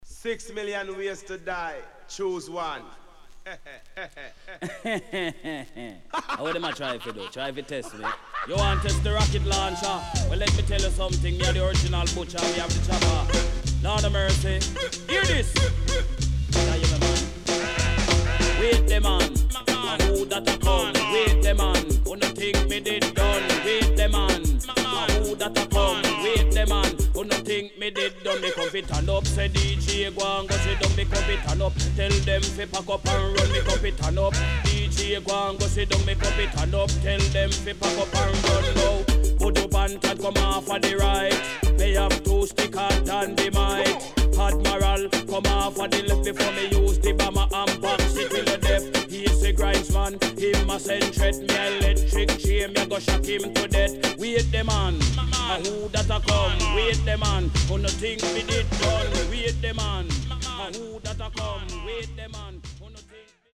HOME > REISSUE USED [DANCEHALL]